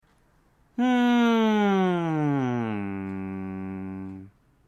次ぎに、音程を変化させ、振動と共鳴を感じてみましょう
唇を閉じ、頭、鼻、喉、胸、お腹の順に響かせるようにハミングをします。
（頭からお腹まで順に）ンーーーーー
ハミング.mp3